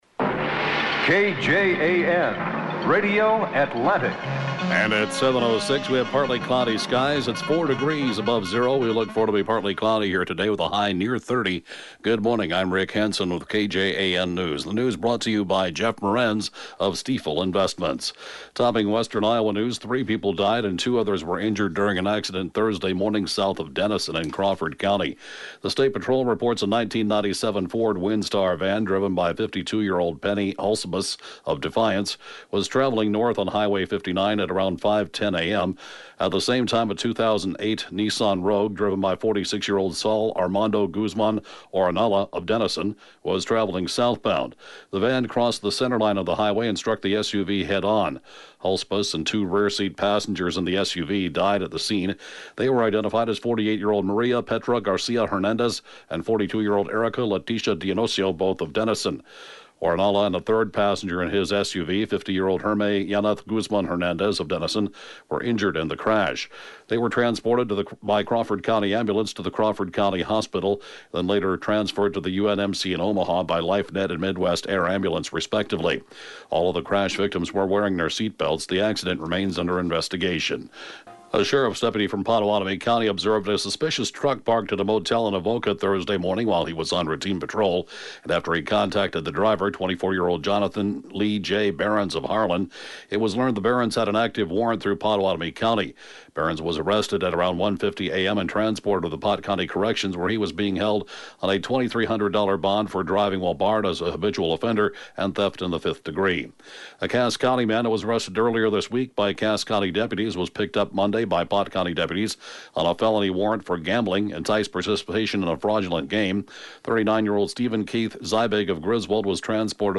(Podcast) KJAN Morning News & Funeral report, 2/1/2019